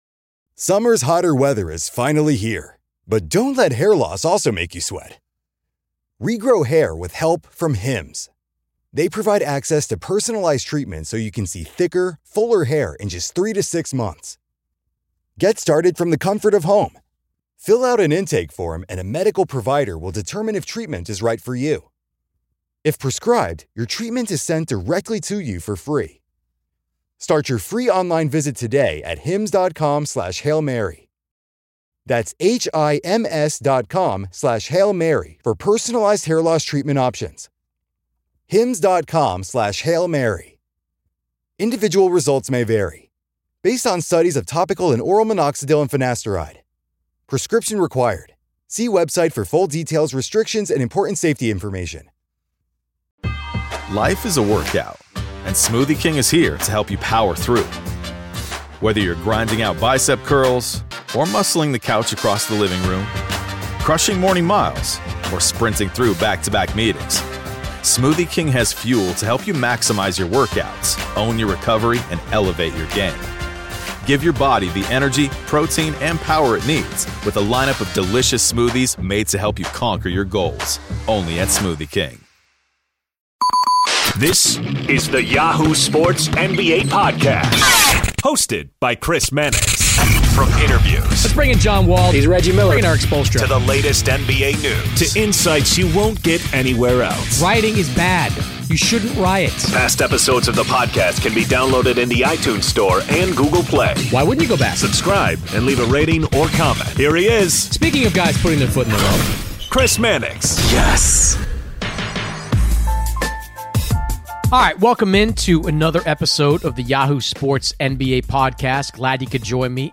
Fran Fraschilla The Crossover NBA Show SI NBA Basketball, Sports 4.6 • 641 Ratings 🗓 17 July 2018 ⏱ 40 minutes 🔗 Recording | iTunes | RSS 🧾 Download transcript Summary Joining Chris Mannix of Yahoo Sports this week is Fran Fraschilla, former college coach and college basketball analyst.